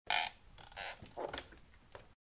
Звуки стула
Звук скрипящего покачивания стула